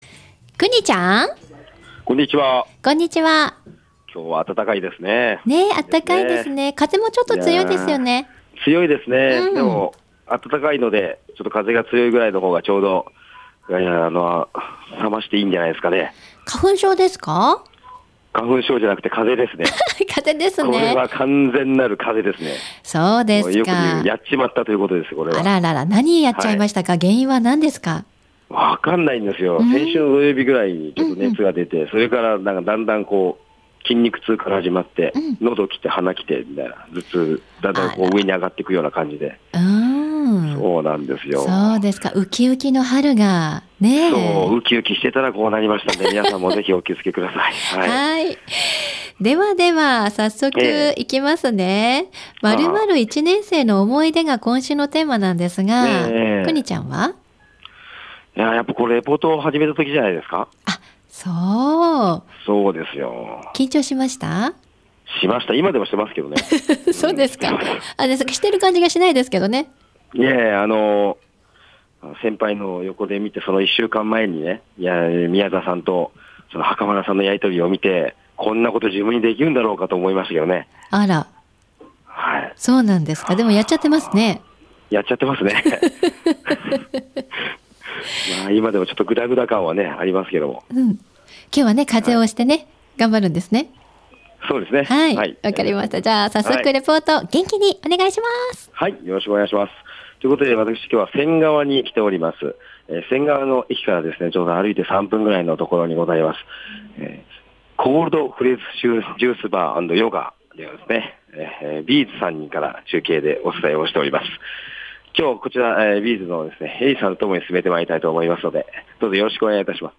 まずは聞きにくい声でお送りしてしまったことをお詫びいたします。